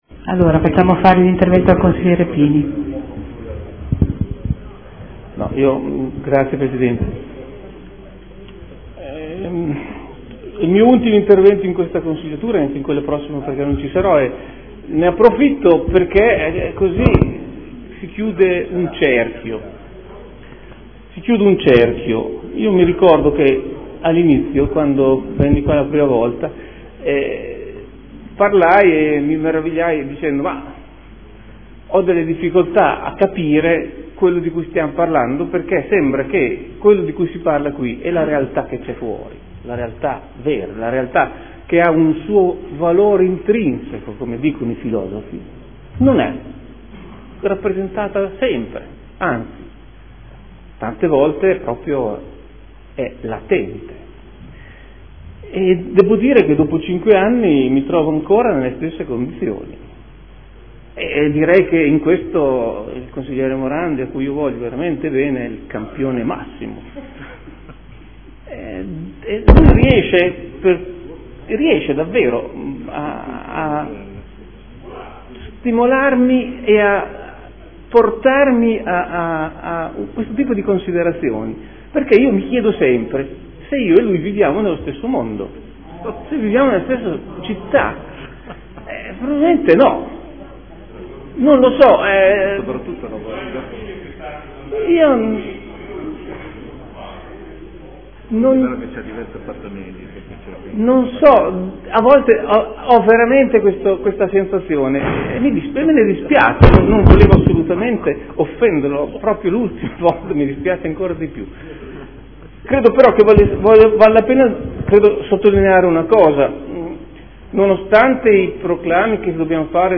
Luigi Alberto Pini — Sito Audio Consiglio Comunale
Seduta del 28/04/2014. Imposta Unica Comunale (IUC) – Rideterminazione regolamentare delle aliquote, detrazioni e dei termini di applicazione dell’Imposta Municipale Propria (IMU) e del Tributo sui Servizi Indivisibili (TASI), delle tariffe e delle scadenze di pagamento del Tributo sui Rifiuti (TARI) per l’anno 2014 – Approvazione